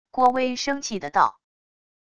郭威生气的道wav音频